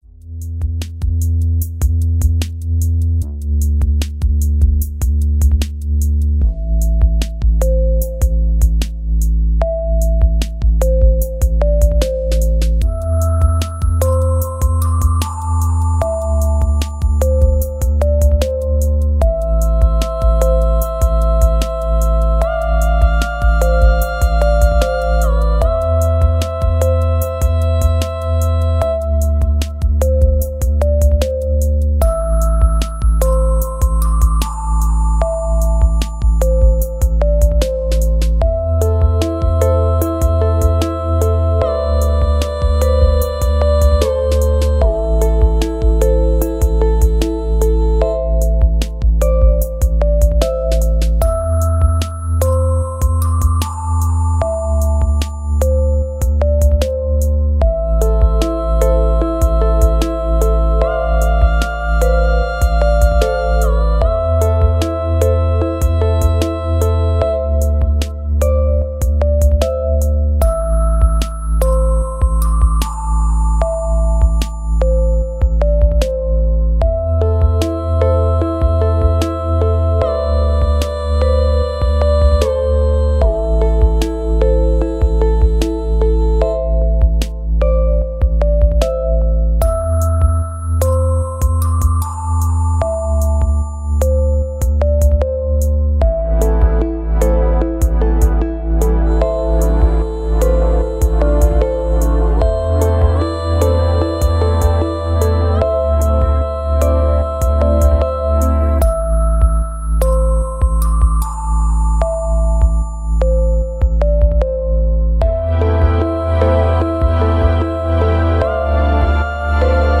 Soul Vibes (Chill, Trance)